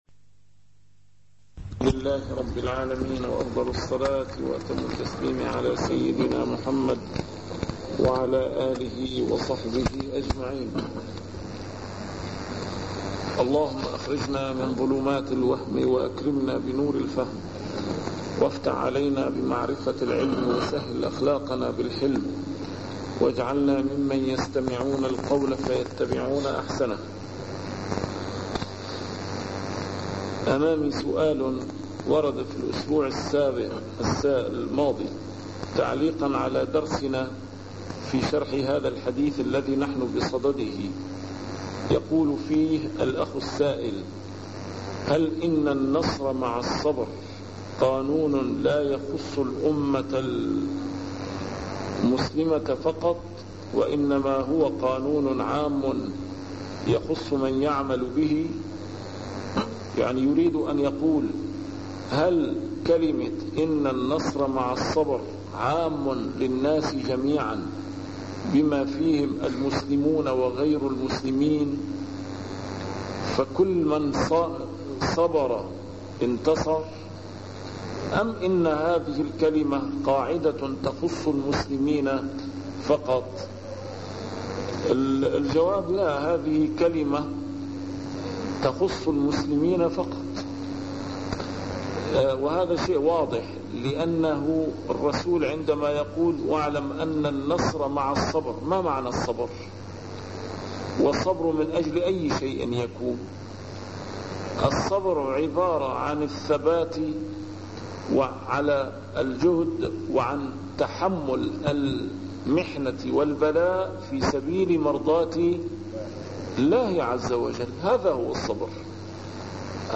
A MARTYR SCHOLAR: IMAM MUHAMMAD SAEED RAMADAN AL-BOUTI - الدروس العلمية - شرح الأحاديث الأربعين النووية - تتمة شرح الحديث التاسع عشر: حديث ابن عباس (يا غلام إني أعلمك كلمات) 65